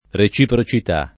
[ re © ipro © it #+ ]